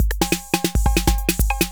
16beat.aiff